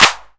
CLAPS 2.wav